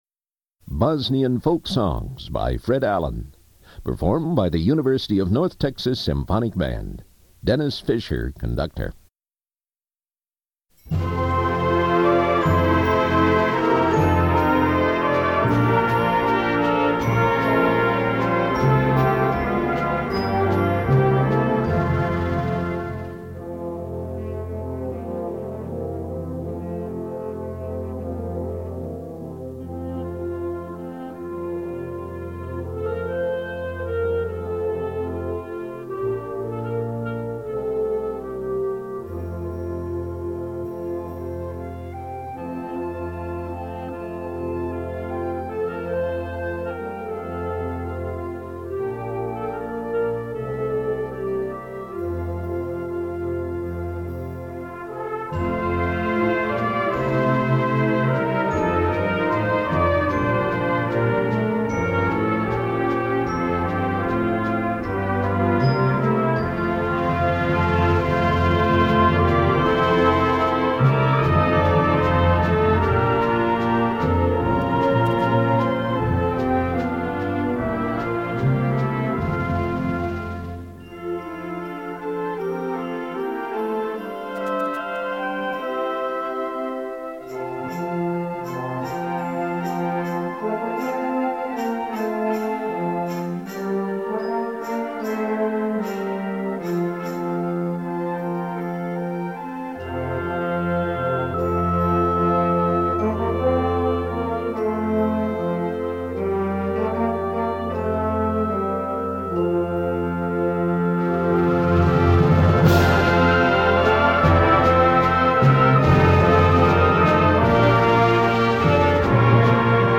Voicing: Concert Band